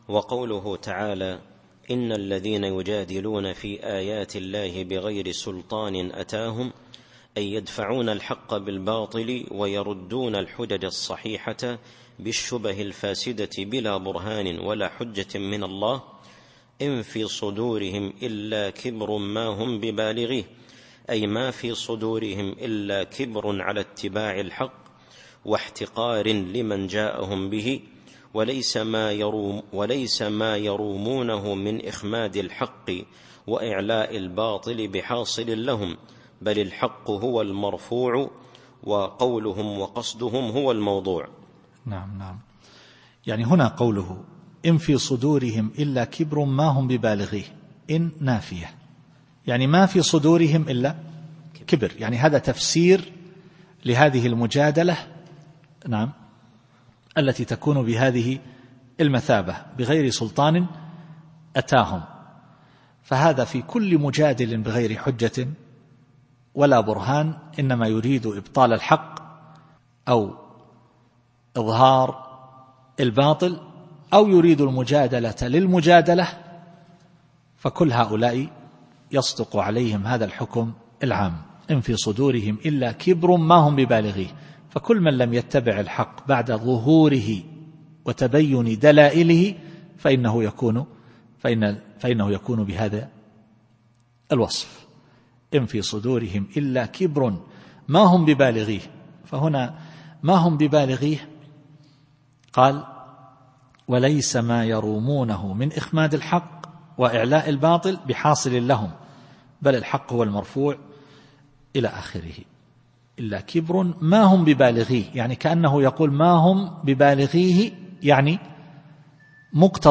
التفسير الصوتي [غافر / 56]